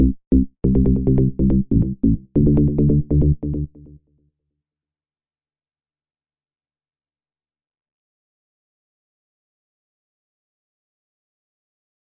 一个由我创造的贝斯。140 BPM